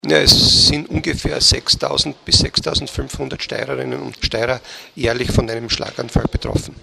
Pressekonferenz zum Thema integrierte Versorgung von Schlaganfallpatienten